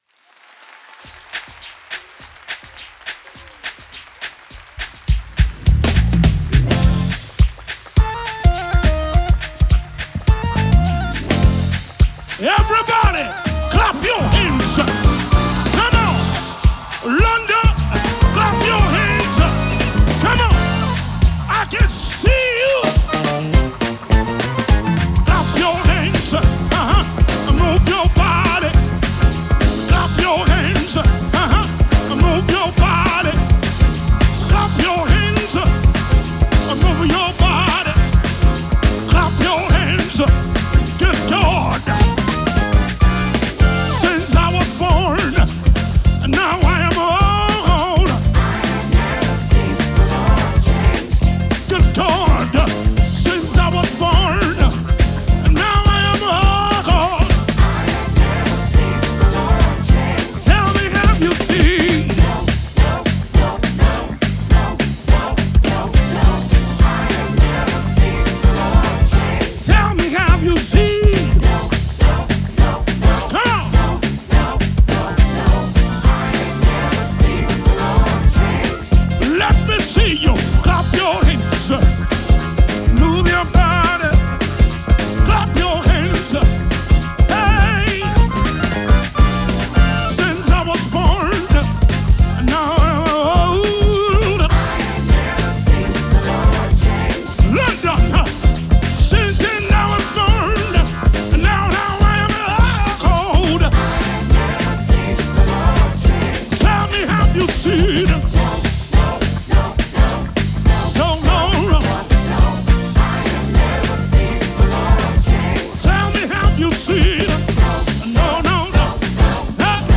Gospel music
is a fast tempo funk. The tone and mood is festive.
with his funky fast pace beat and sing-along lyrics